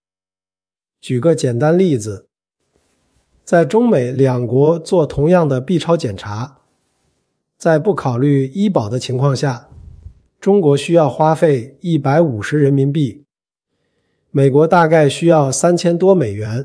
f5tts - F5-TTS wrap module